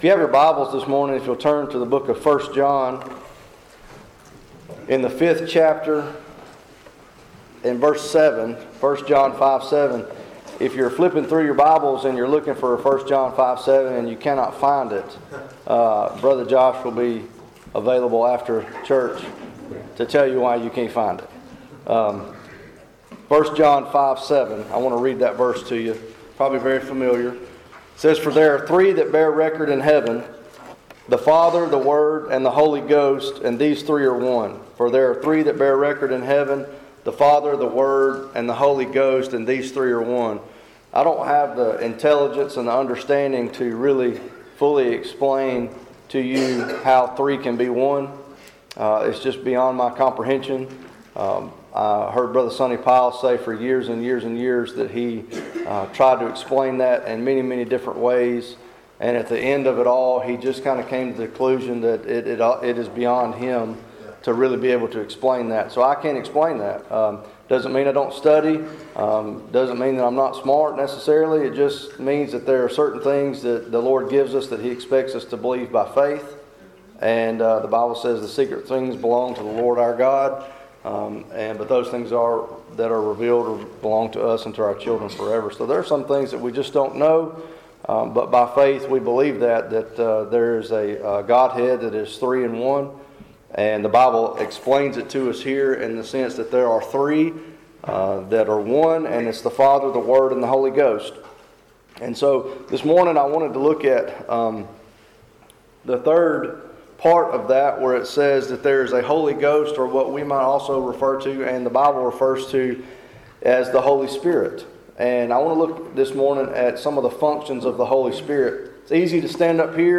In a sermon preached at Vestavia Primitive Baptist Church